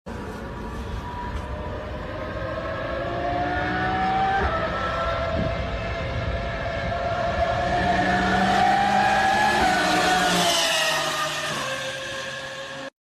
🏎 If you love engine sounds then you are on right place. Enjoy videos of V6, V8, V12, rotary engine and many more tuned vehicles.